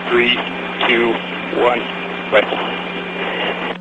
RifleA.ogg